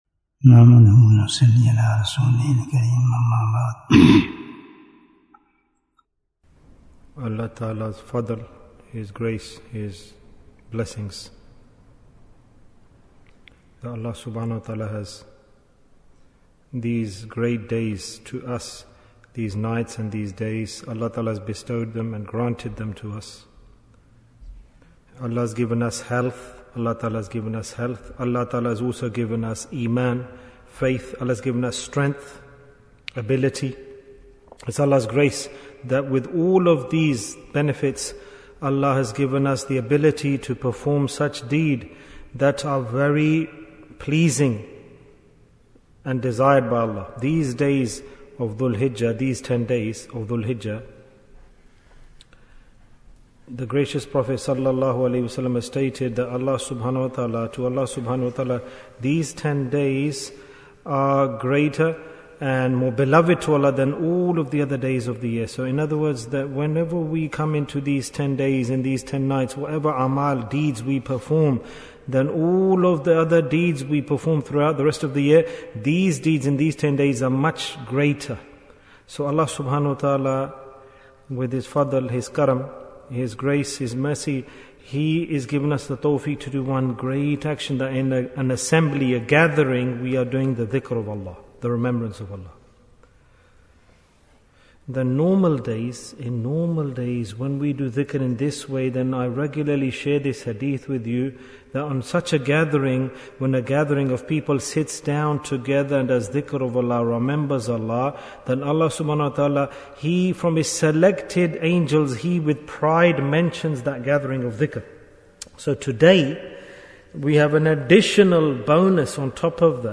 Bayan, 4 minutes